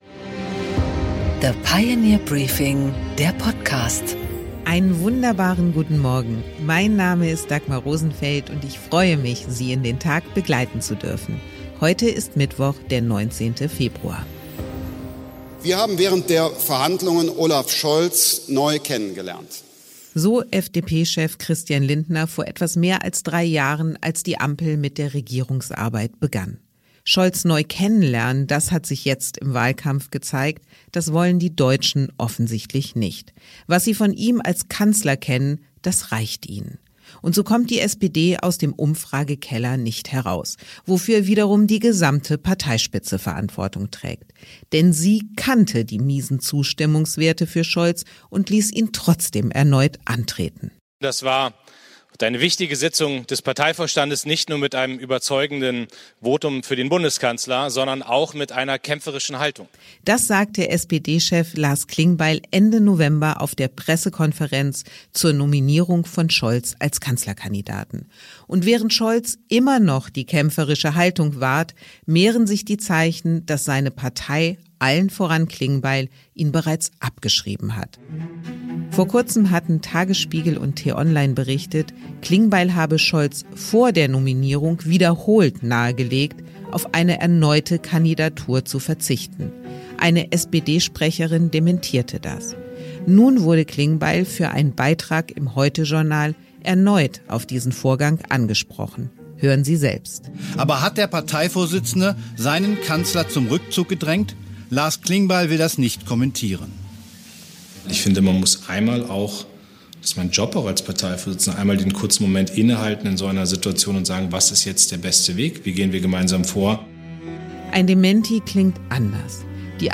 Interview mit Prof. Carlo Masala